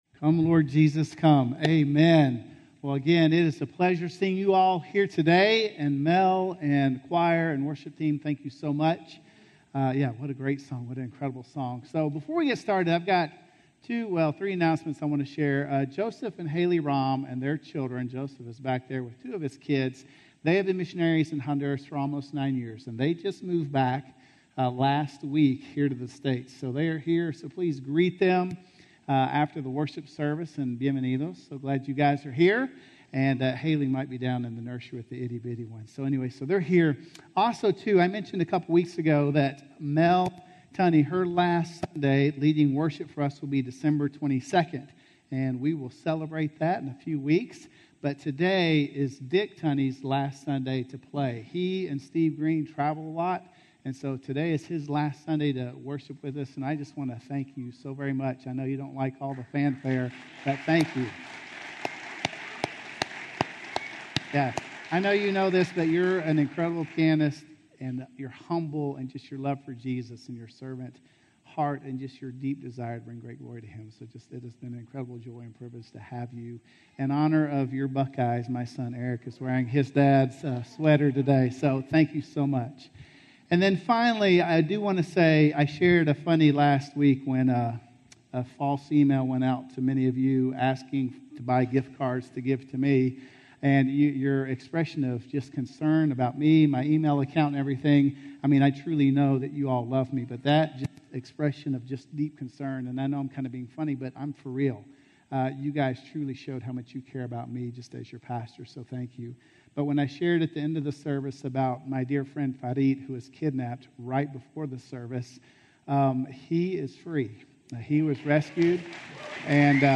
The Throne - Sermon - Woodbine